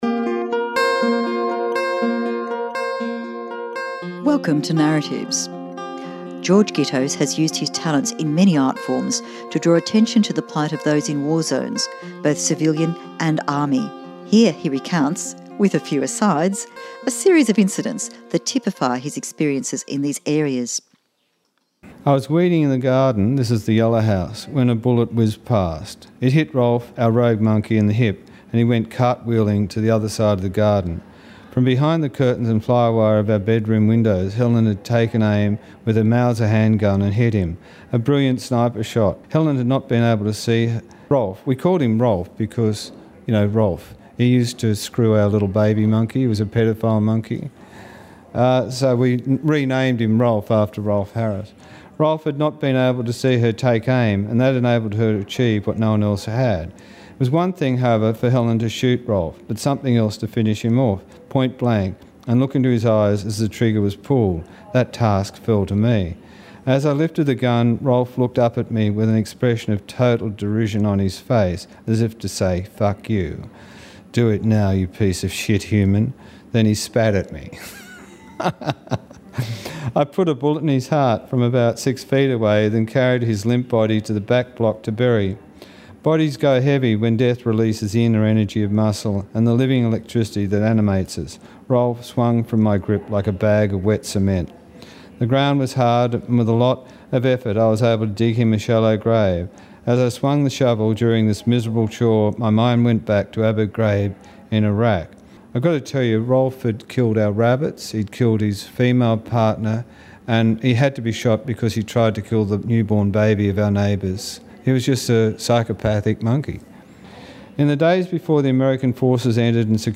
Recorded at the Sydney Writers Festival 2017